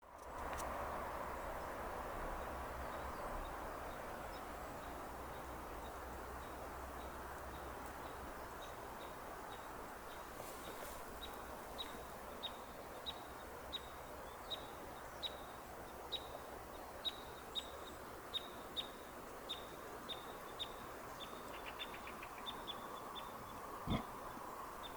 Parrot Crossbill, Loxia pytyopsittacus
Administratīvā teritorijaDundagas novads
Count45
StatusPasses over (transit)